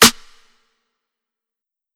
YM Snare 16.wav